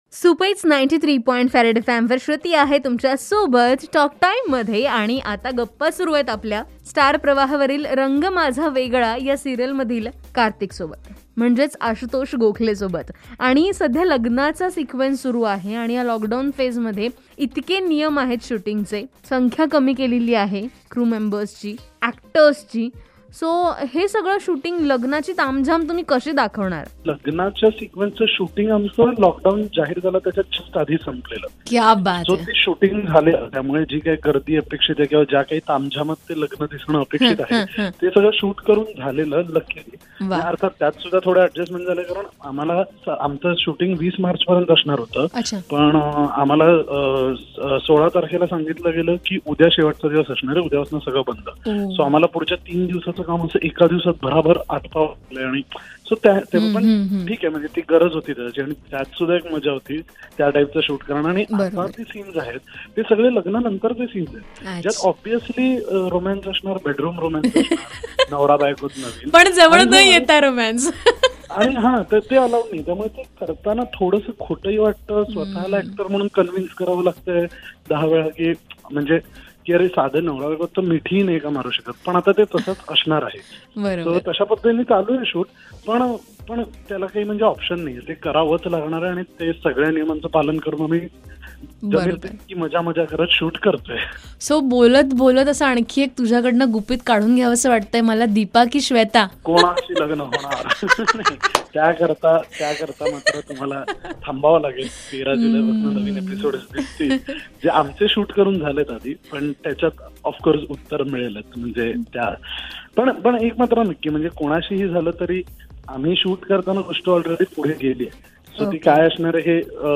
THE MOST AWAITED PART OF THE RANG MAJHA VEGLA SERIAL OF KARTIK WEDDING HAS STARTED AS SHOOTING FOR THE SERIAL HAS BEEN STARTED ..SO LETS HAVE SOME GUPSHUP WITH THE LEAD STAR OF THE SERIAL